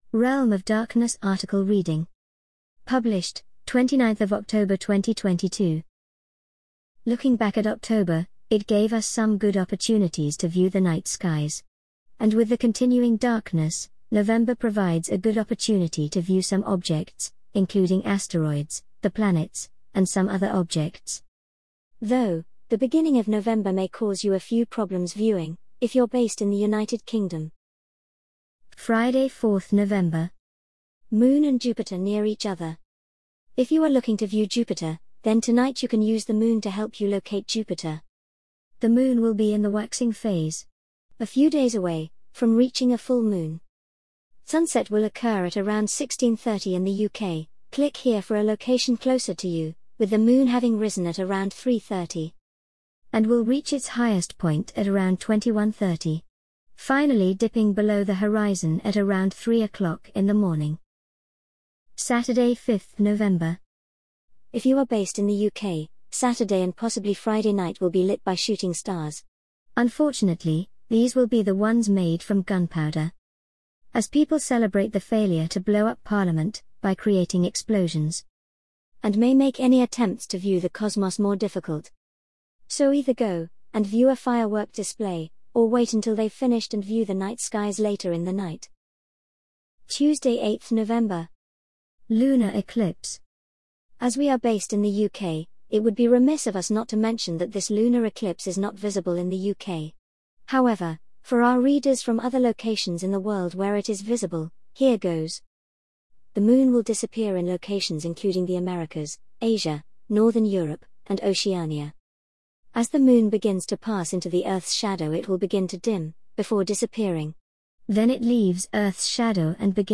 An audio reading of the Realm of Darkness November 2022 Article